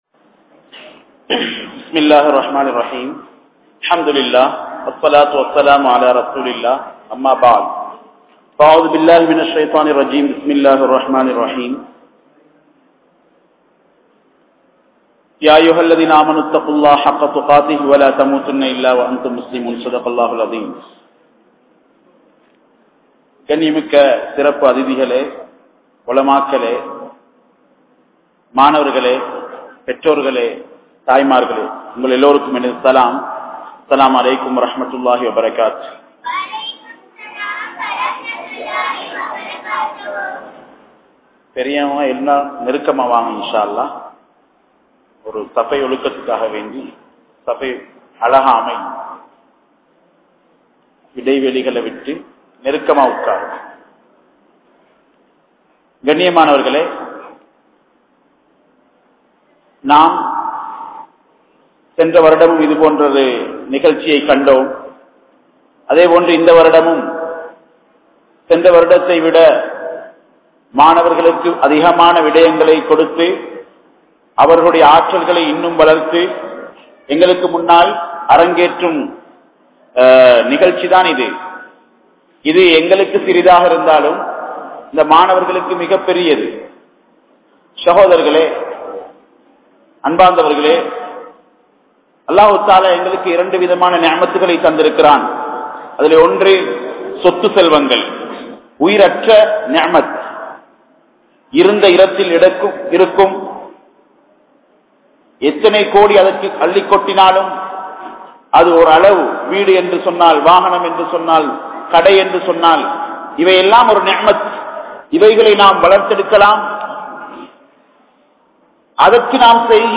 Pillaihal Soathanaihala? (பிள்ளைகள் சோதனைகளா?) | Audio Bayans | All Ceylon Muslim Youth Community | Addalaichenai
Kandy,Mahaiyawa , Jamiul Khairath Jumua Masjith